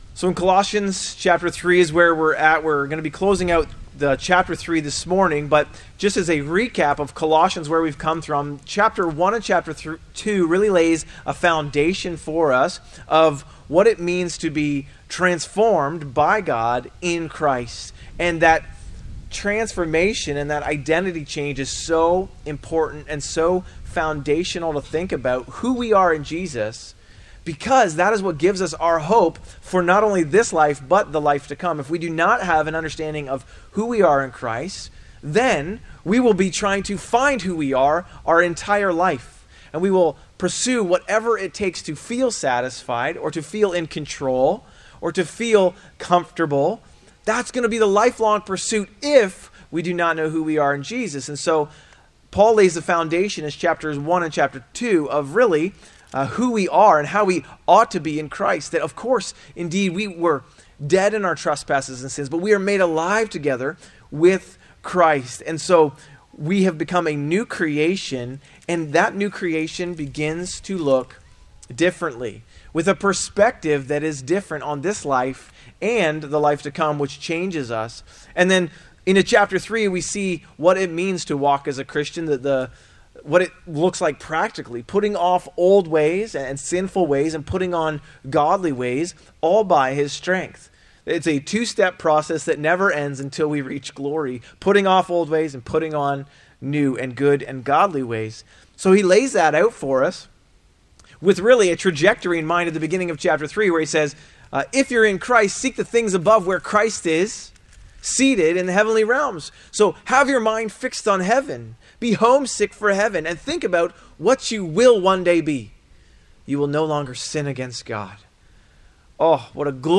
A message from the series "In Christ Alone."